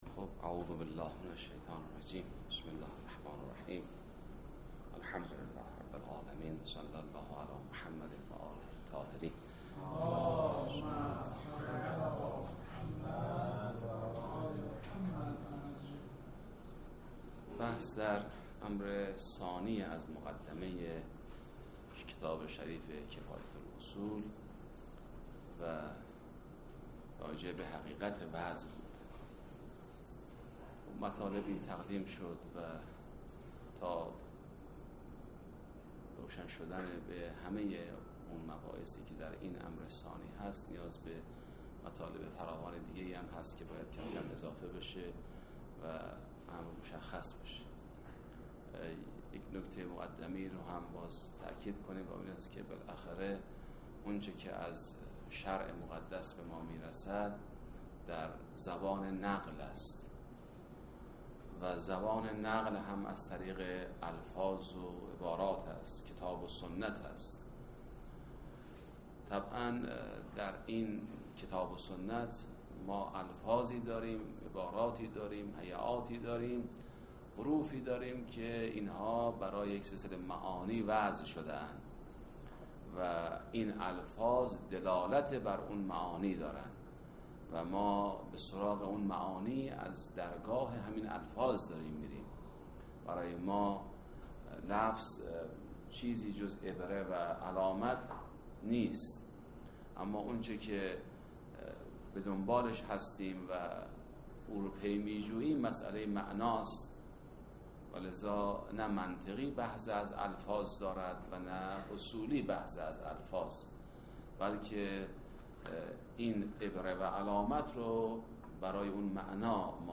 خارج اصول- جلسه22